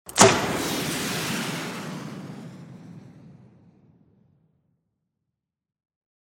Звук выстрела базуки